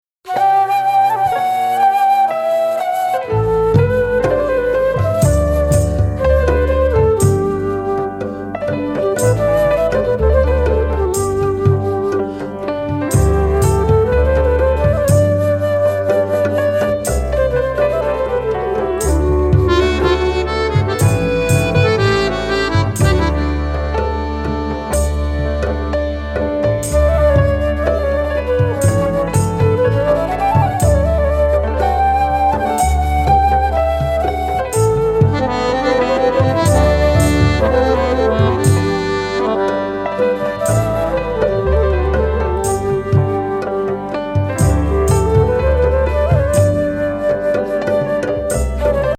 アジア・アコーディオン奏者最高峰